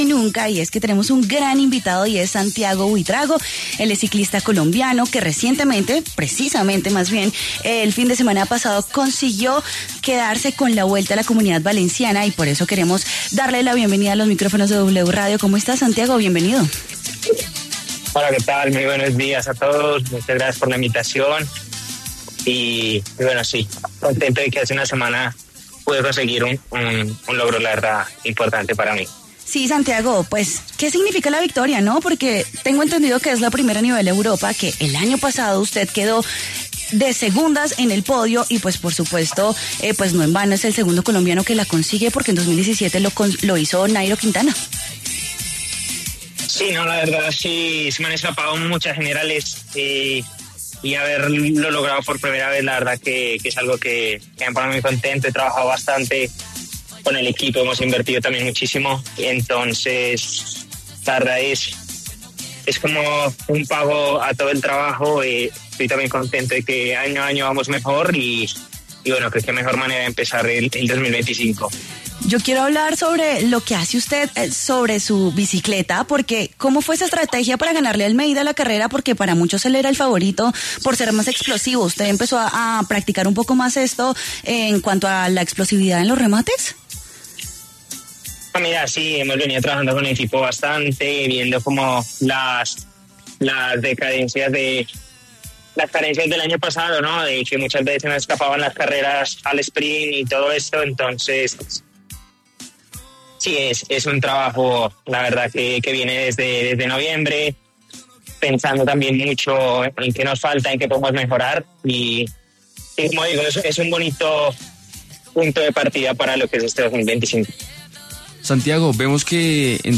Santiago Buitrago, ciclista colombiano, pasó por W Fin de Semana y habló sobre su experiencia al coronarse como campeón de la Vuelta a la Comunidad Valenciana 2025 en su edición #75 el pasado 9 de febrero.